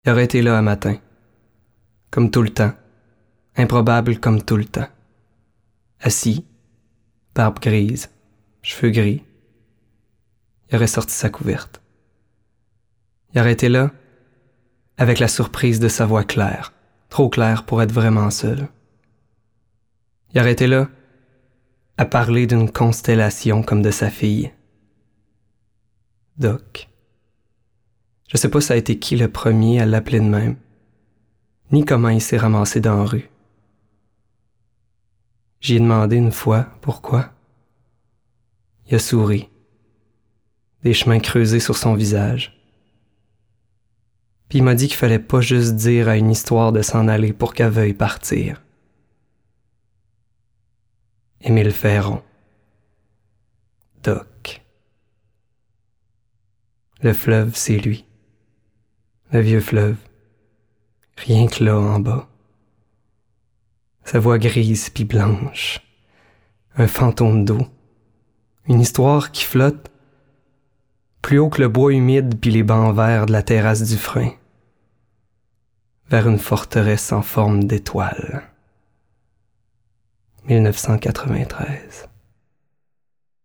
Caractéristiques Décidée, amicale, douce, timbre grave
Voix d'homme
L'Almageste (extrait) - Voix narrative dramatique / Québécois soutenu
Narration 2013 1:23 2,54Mo